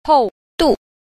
5. 厚度 – hòudù – hậu độ (độ dày)